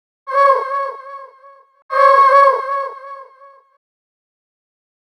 Ridin_ Dubs - FX Vox.wav